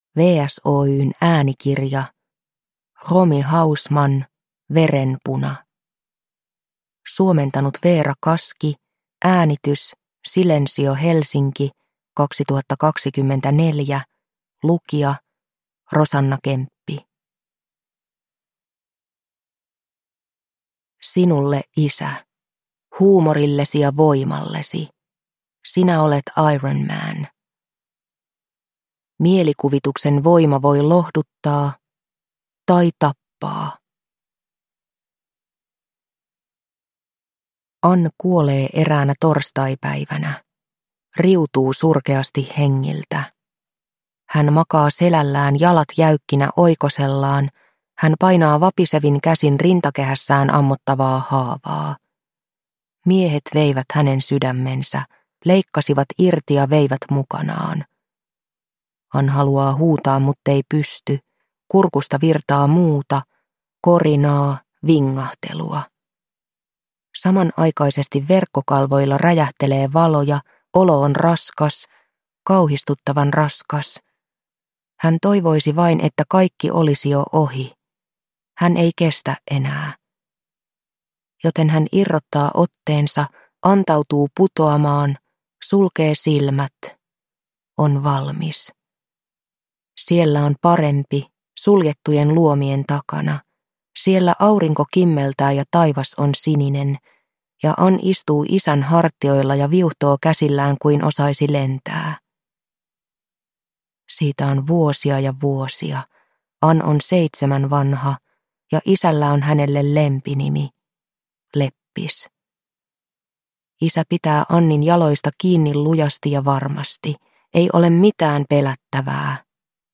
Downloadable Audiobook
Ljudbok
Narrator